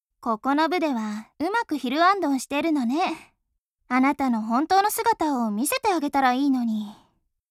ボイス1 ボイス2 ボイス3 ボイス4 秋人の走る才能に惚れ込んで他校から転校してくる女の子。